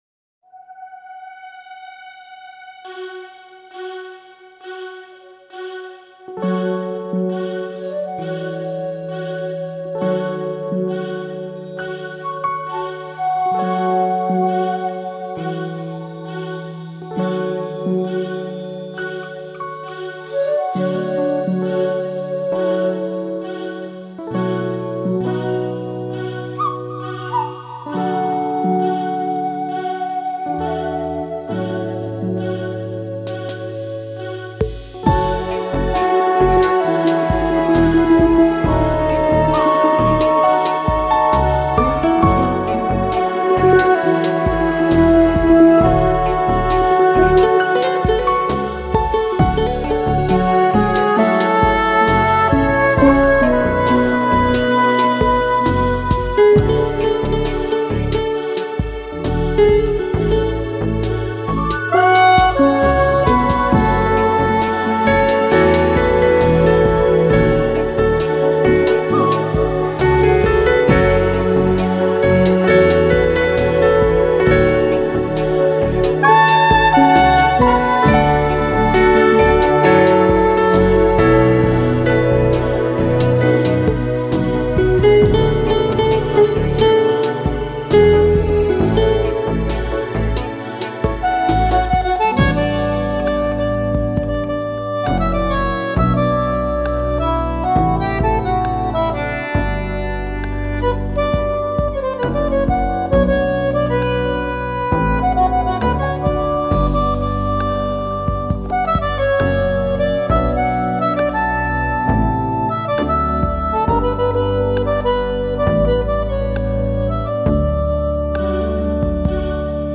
专辑类型：节奏蓝调、雷鬼、Hip-Hop
混合节奏蓝调、Hip-Hop、雷鬼等热情洋溢的海滩音乐，没钱出国时的最佳选择！